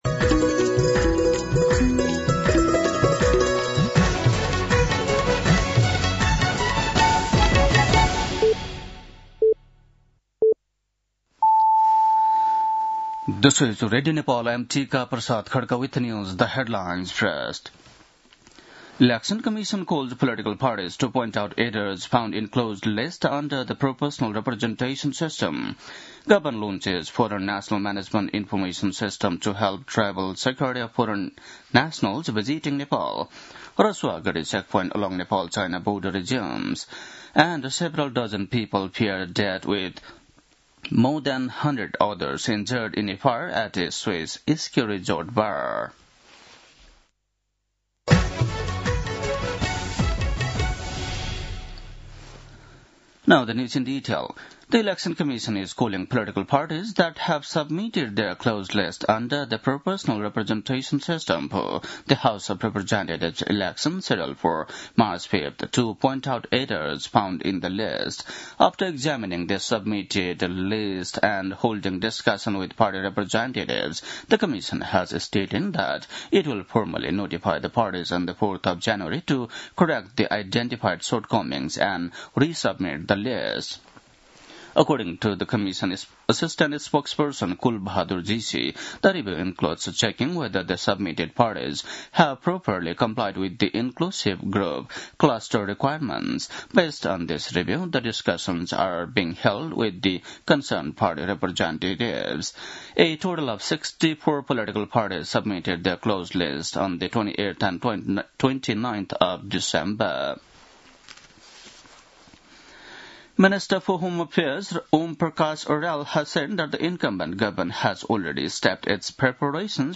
An online outlet of Nepal's national radio broadcaster
बेलुकी ८ बजेको अङ्ग्रेजी समाचार : १७ पुष , २०८२